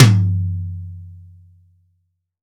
Index of /90_sSampleCDs/AKAI S6000 CD-ROM - Volume 3/Drum_Kit/ROCK_KIT1
MIX2 MTOM -S.WAV